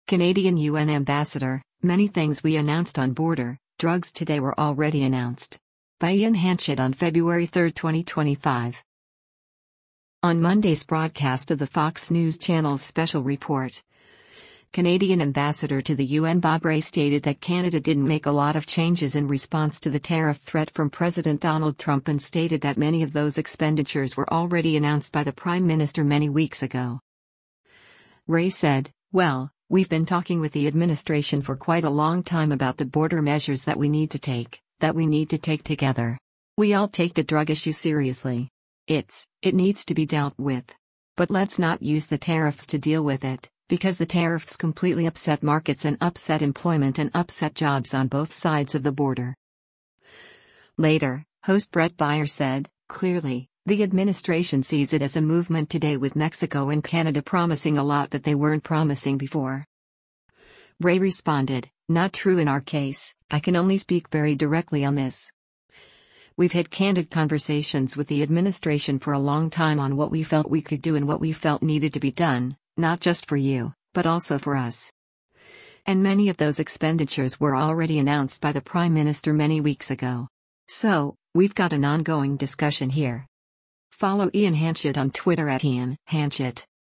On Monday’s broadcast of the Fox News Channel’s “Special Report,” Canadian Ambassador to the U.N. Bob Rae stated that Canada didn’t make a lot of changes in response to the tariff threat from President Donald Trump and stated that “many of those expenditures were already announced by the prime minister many weeks ago.”
Later, host Bret Baier said, “Clearly, the administration sees it as a movement today with Mexico and Canada promising a lot that they weren’t promising before.”